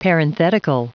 Prononciation du mot parenthetical en anglais (fichier audio)
Prononciation du mot : parenthetical